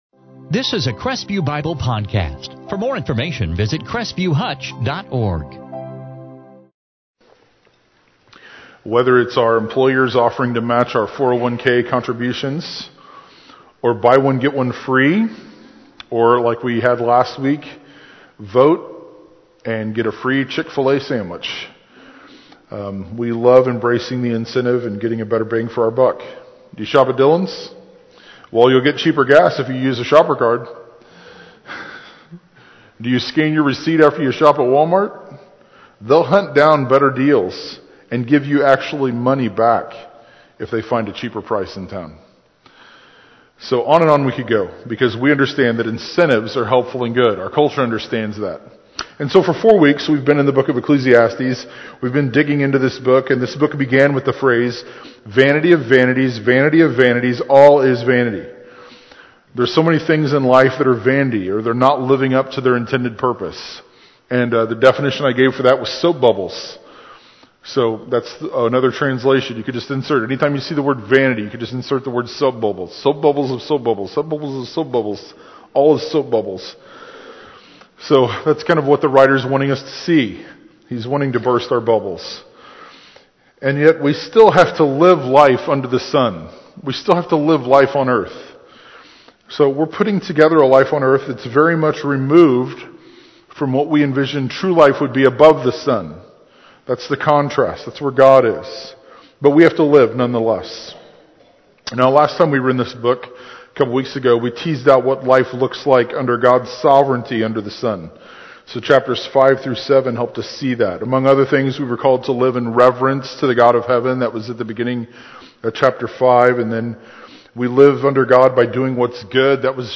Topic Worship